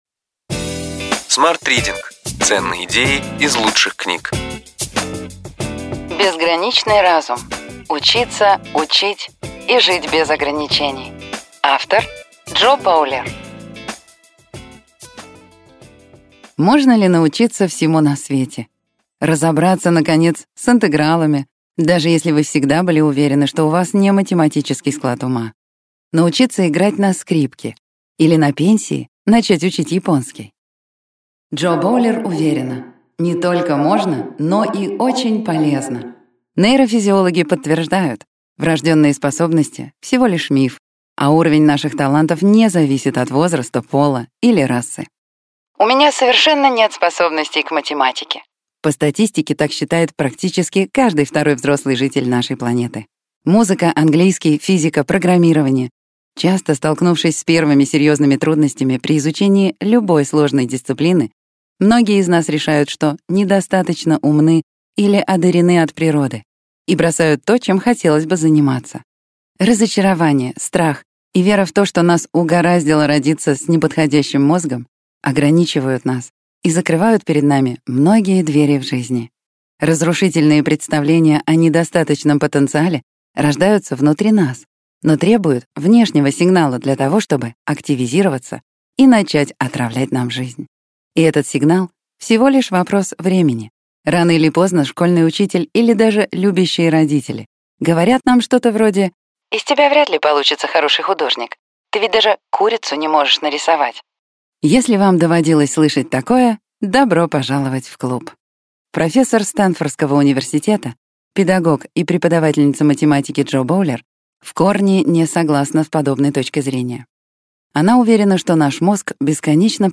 Аудиокнига Ключевые идеи книги: Безграничный разум.